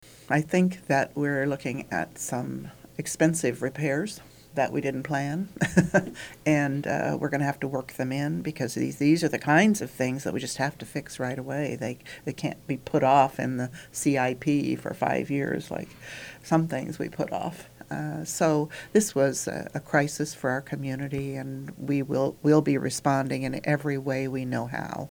Manhattan Mayor Linda Morse was also in-studio on that episode of In Focus. She said the repairs are going to be costly, but the city will have to figure out a way to fit them into their tight budget.